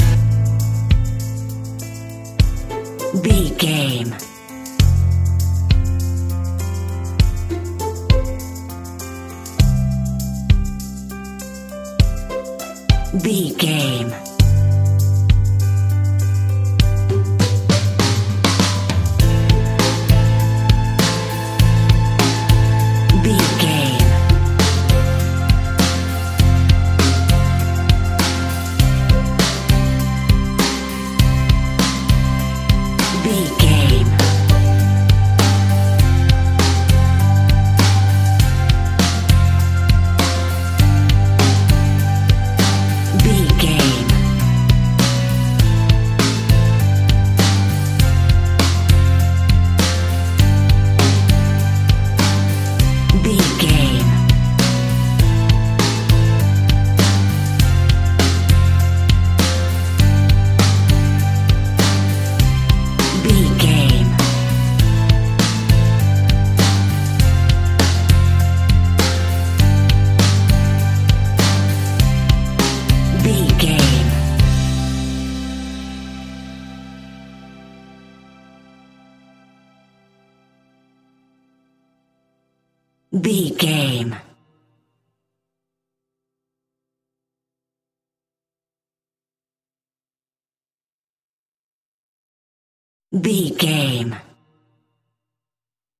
Ionian/Major
calm
happy
smooth
uplifting
electric guitar
bass guitar
drums
pop rock
indie pop
organ